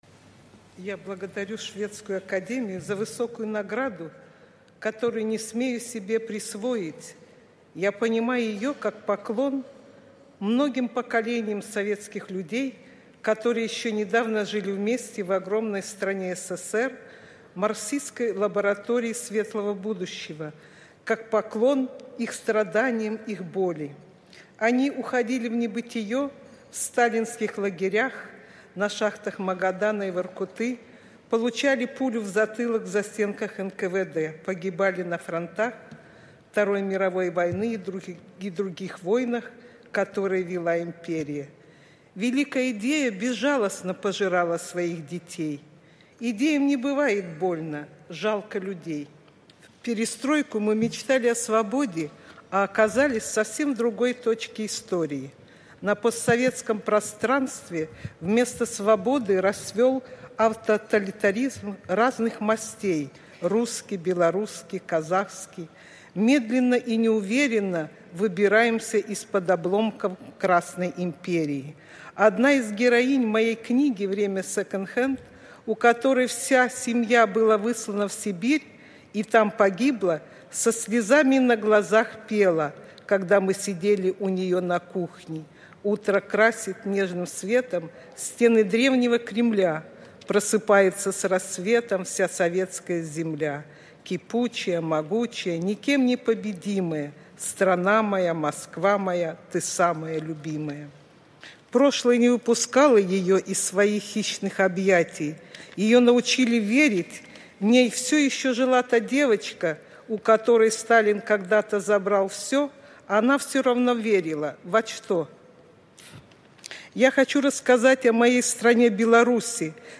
На банкеце ў Блакітнай залі Стакгольмскай ратушы першая беларуская нобэлеўская ляўрэатка згадала Адамовіча і Быкава і расказала сьвету пра Беларусь.
Сьвятлана Алексіевіч. Прамова на Нобэлеўскім банкеце